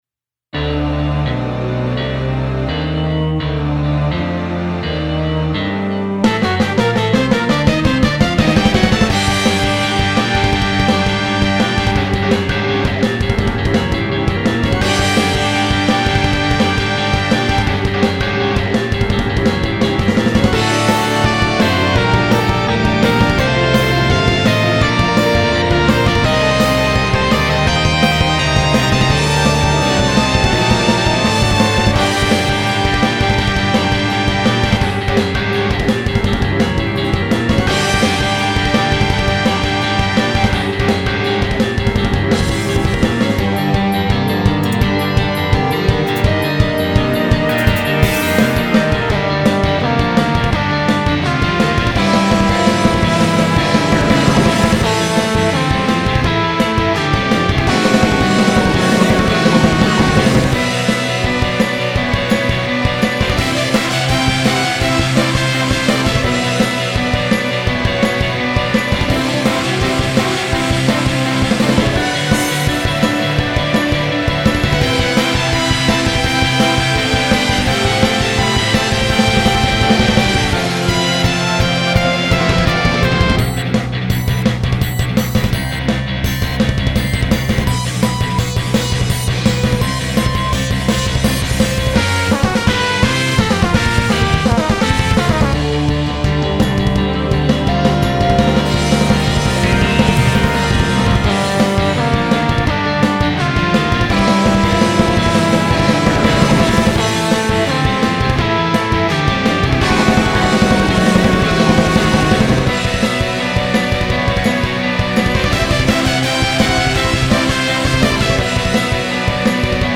カバー・アレンジ曲
ちょっと息抜きにアレンジ度薄めで何か別の曲を作りたくなったので、
ドラムフレーズ作ってる間Brufordのこと考えてた。